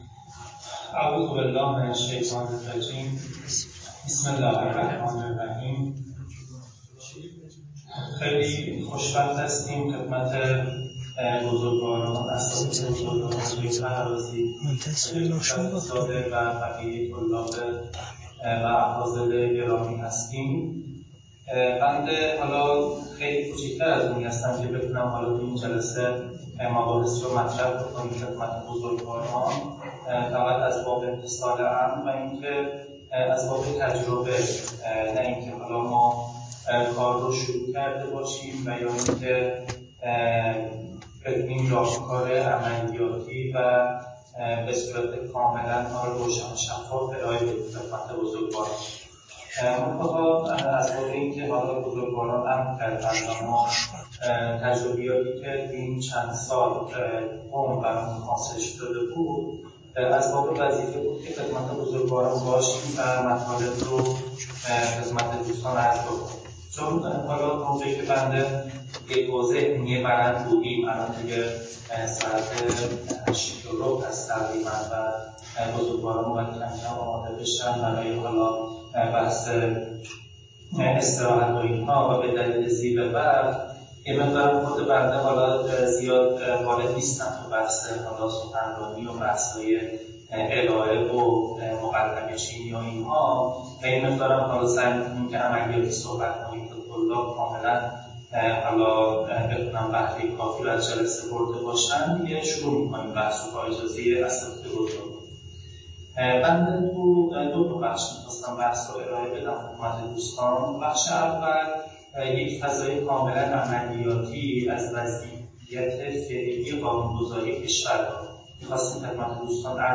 🔰وبینار مجازی
🔹برگزارشده در مدرسه علمیه صاحب الزمان عج مرند